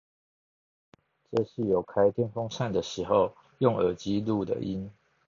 ▼ 「【Jabra】Elite 85t Advanced ANC 降噪真無線耳機」開電風扇製造風聲環境下錄音檔